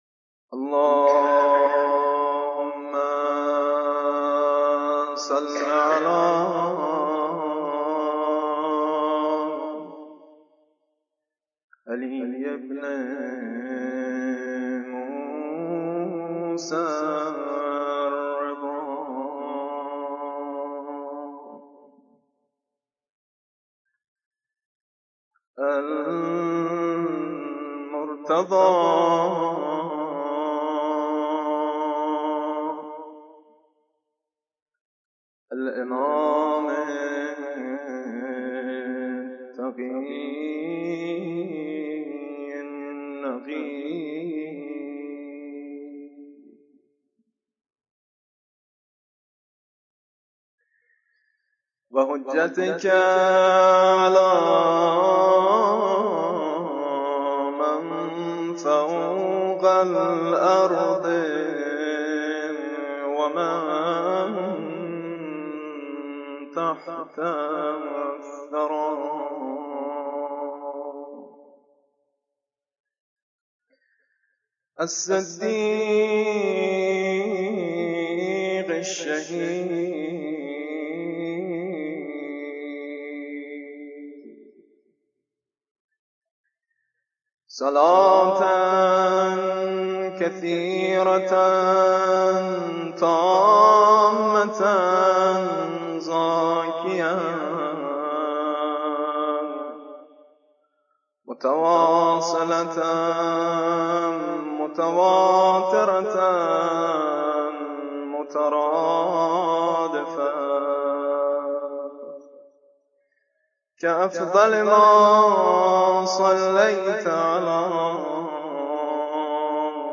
مدیحه خوانی در ولادت امام رضا 11 ذی القعده سال 1420هـ.ق